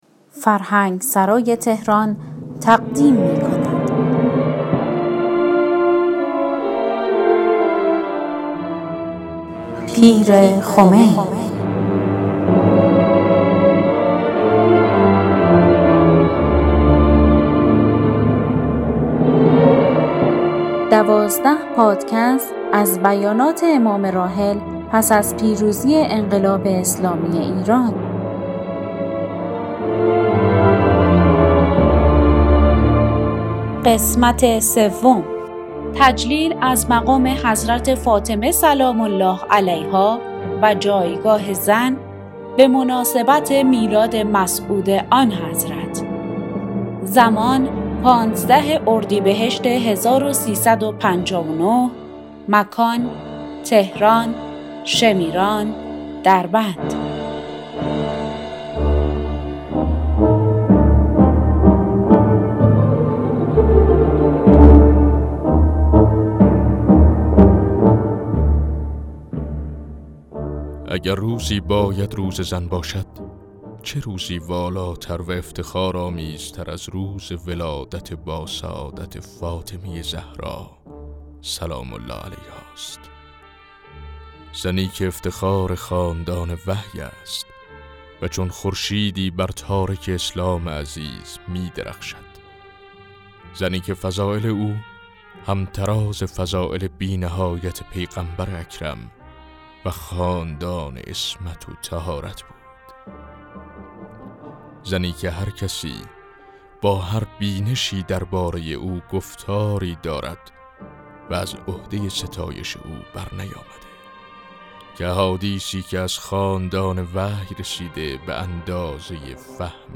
در ادامه خوانش سخنان امام (ره) را درباره مقام حضرت فاطمه زهرا(س) و جایگاه بانوان که در اردیبهشت ماه سال 1359 در تهران ایراد کردند، می‌شنویم.